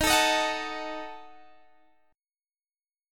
D#dim Chord
Listen to D#dim strummed